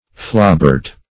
Search Result for " flobert" : The Collaborative International Dictionary of English v.0.48: Flobert \Flo"bert\, n. (Gun.)
flobert.mp3